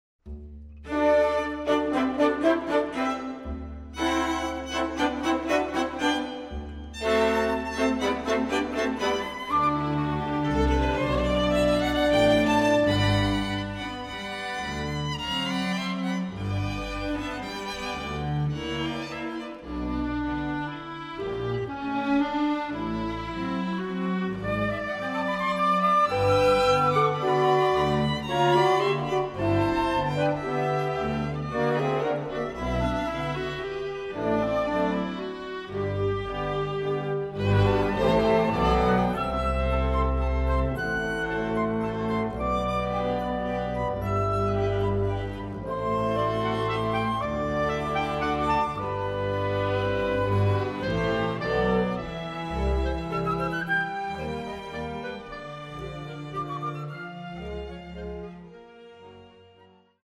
pro komorn� soubor